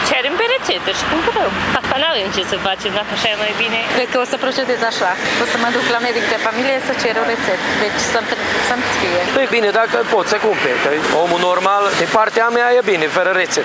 Târgumureșenii sunt supărați că trebuie să facă un drum la medicul de familie pentru a li se prescrie aceste pastile.